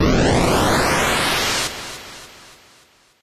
snd_rocket_long.wav